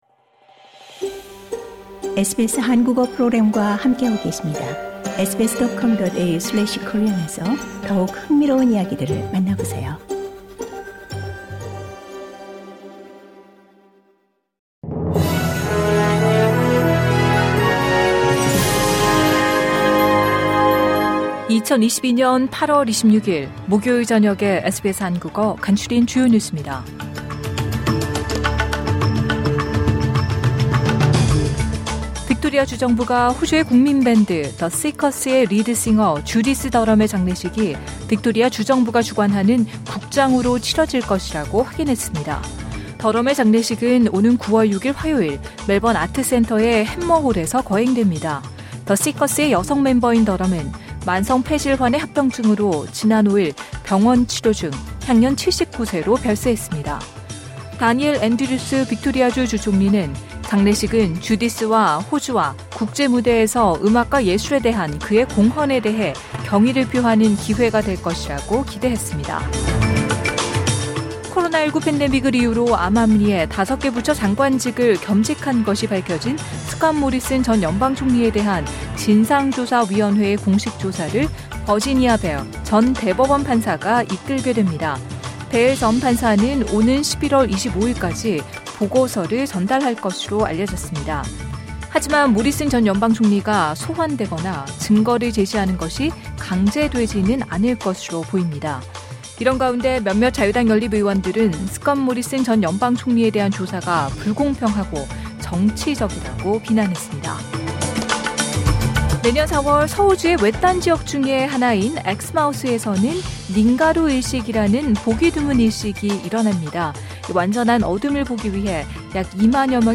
SBS 한국어 저녁 뉴스: 2022년 8월 26일 목요일